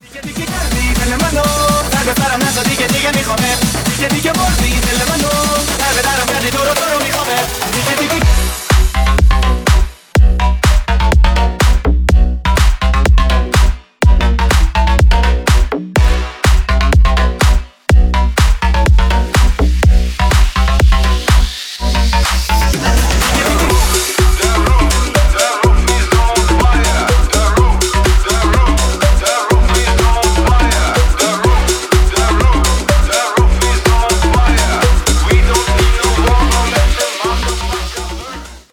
• Качество: 320 kbps, Stereo
Ремикс
клубные